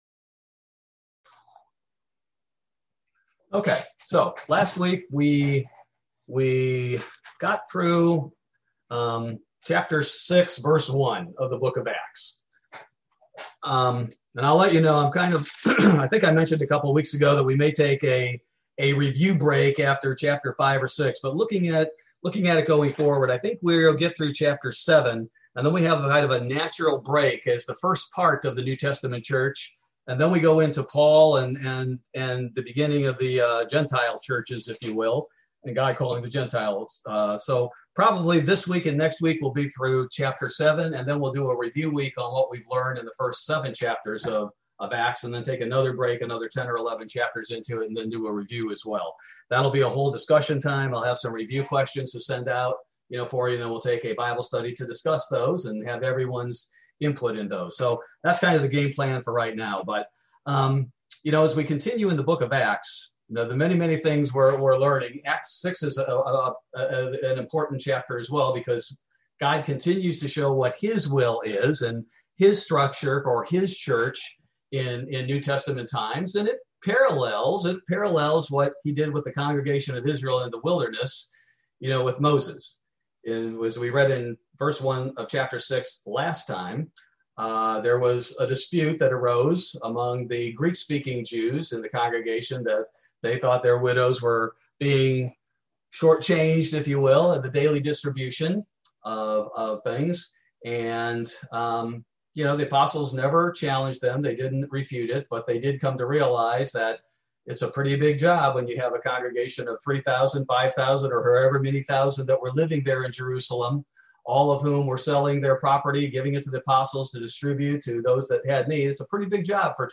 Bible Study: June 16, 2021